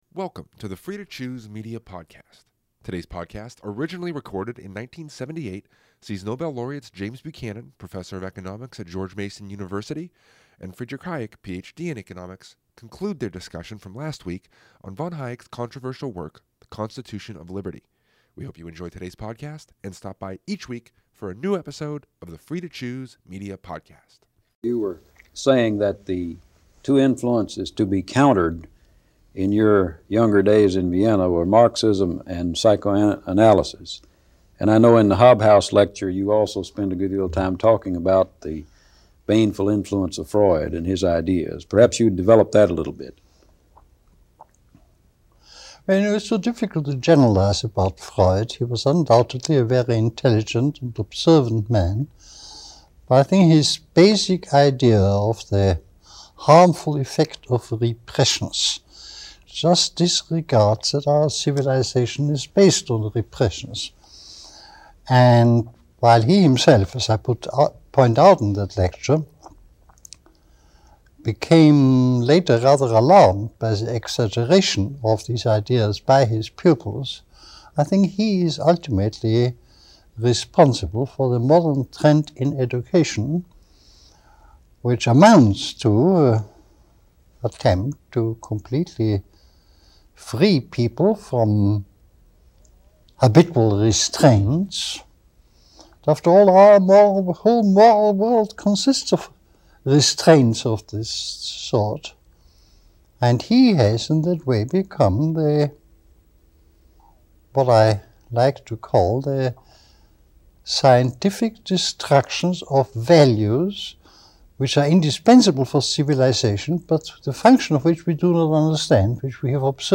Listen to the conclusion of this conversation between two of the most influential economists over the last half-century, Friedrich Hayek and James Buchanan. Centered around the American Constitution, some of these views remain as controversial now as they did when it was originally recorded, back in 1978.